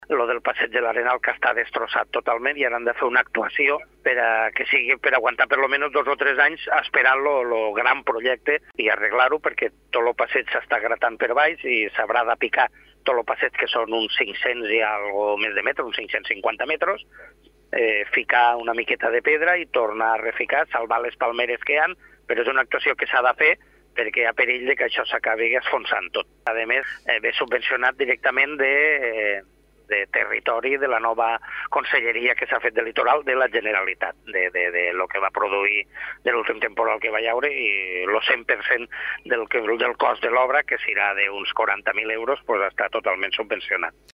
Ho explica Sebastià Castañeda, tinent d’alcaldia de l’Ampolla: